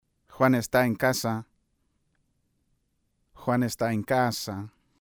El dialecto mexicano norteño y el dialectos argentino porteño.